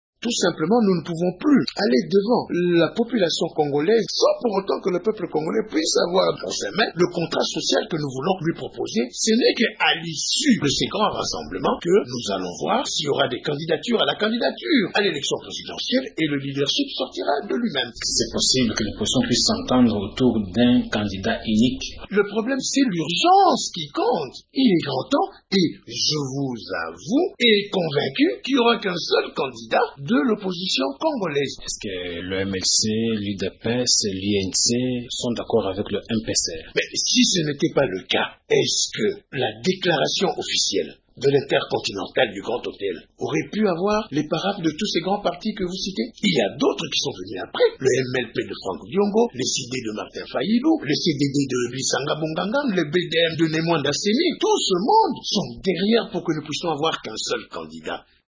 Au cours d’un point de presse, samedi 22 janvier à Kinshasa, le président du Mouvement du peuple congolais pour la République, Jean-Claude Vuemba a annoncé que l’opposition politique entend se choisir un candidat unique à l’élection présidentielle de 2011.